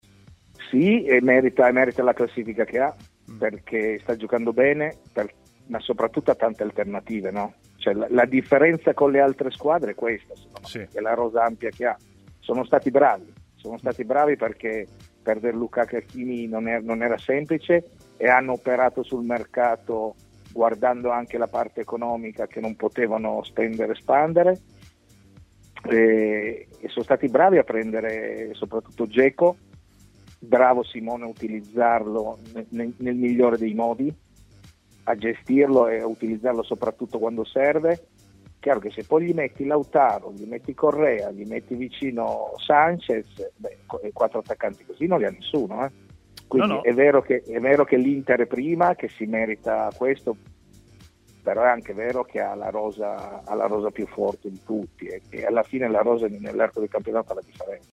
Roberto Cravero, ex difensore di Torino, Cesena e Lazio, è intervenuto a Stadio Aperto, trasmissione pomeridiana di TMW Radio, dove ha parlato dell'Inter, analizzando il significato dei 4 punti di vantaggio su Milan e Napoli.